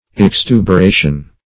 Search Result for " extuberation" : The Collaborative International Dictionary of English v.0.48: Extuberation \Ex*tu`ber*a"tion\, n. [L. extuberatio.]